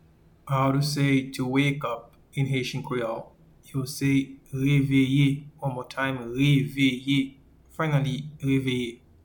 Pronunciation:
to-Wake-up-in-Haitian-Creole-Reveye-1.mp3